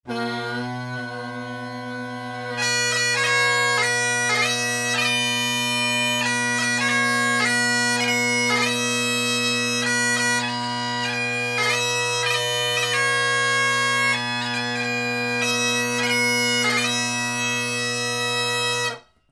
More audio samples recorded in my home studio:
Rock Out With Your Bagpipe Out™